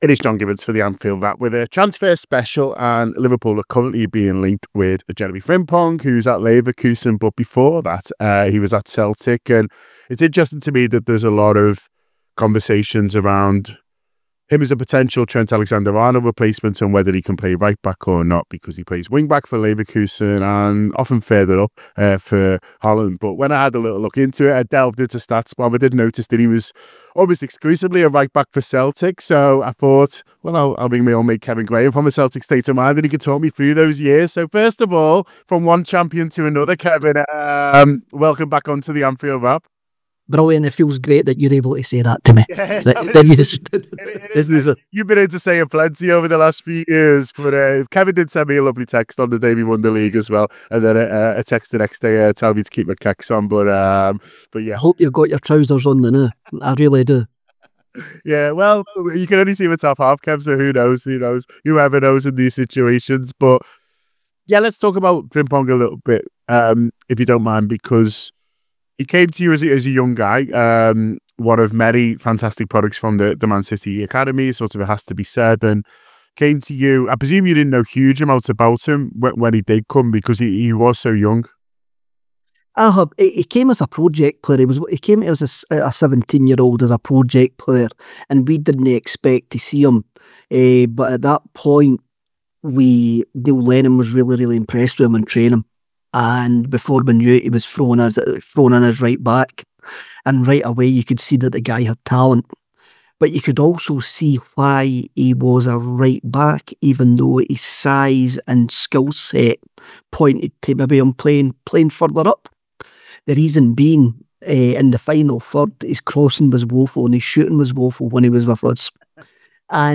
Below is a clip from the show – subscribe for more on the links between Liverpool and Jeremie Frimpong…